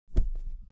دانلود آهنگ تصادف 26 از افکت صوتی حمل و نقل
جلوه های صوتی
دانلود صدای تصادف 26 از ساعد نیوز با لینک مستقیم و کیفیت بالا